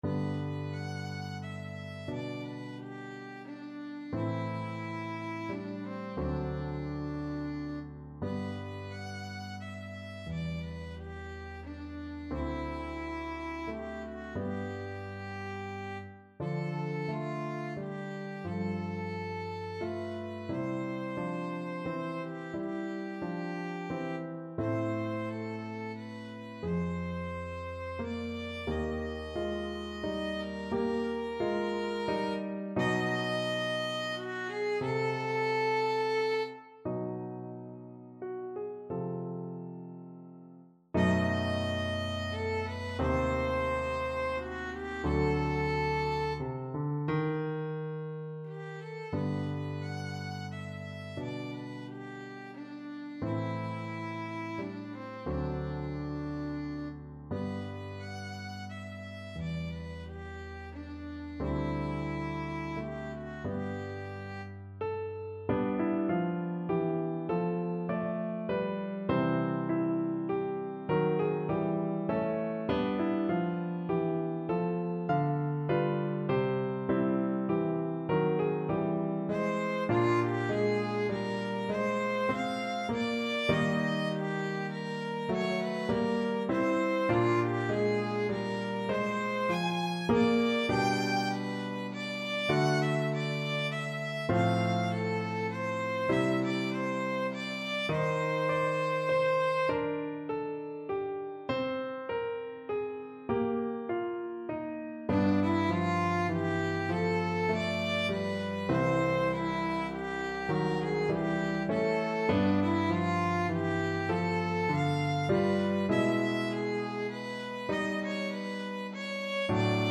= 88 Trs lent
Classical (View more Classical Violin Music)